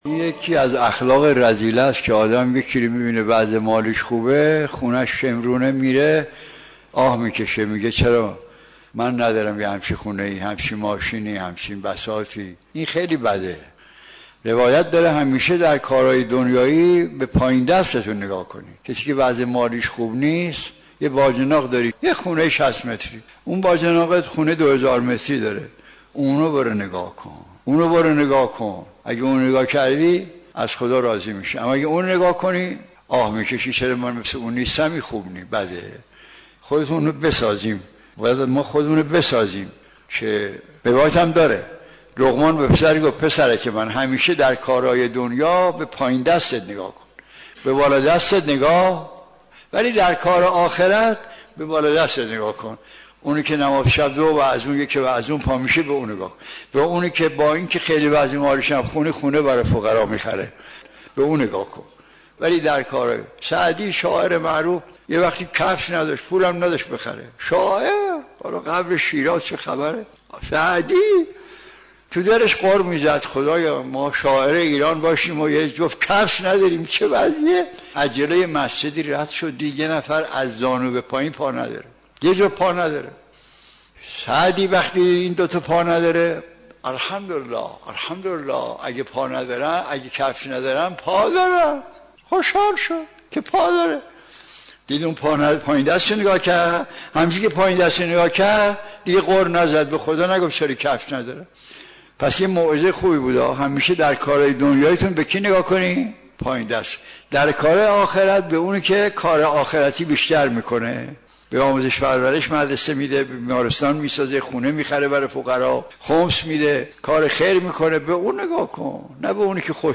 مجموعه حاضر سلسله درس های اخلاق و احکام آیت الله مجتهدی تهرانی می باشد .